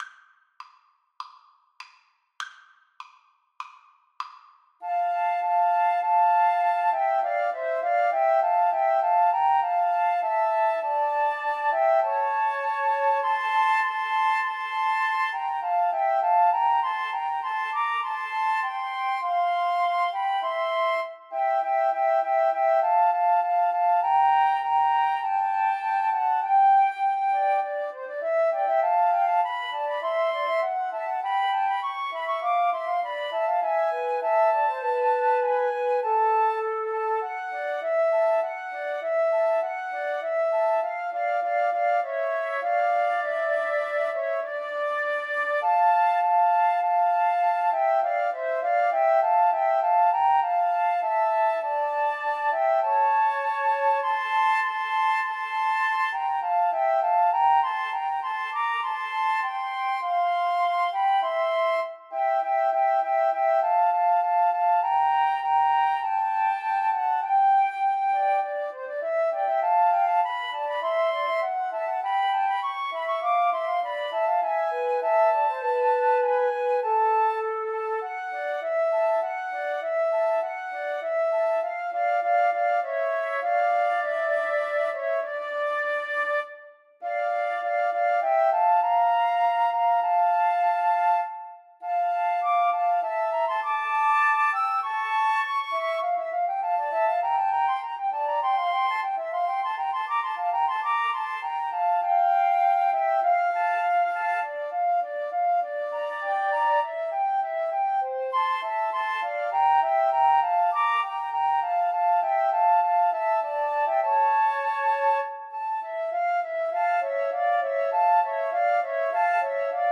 Free Sheet music for Flute Trio
F major (Sounding Pitch) (View more F major Music for Flute Trio )
4/4 (View more 4/4 Music)
[Moderato]
Flute Trio  (View more Intermediate Flute Trio Music)
Classical (View more Classical Flute Trio Music)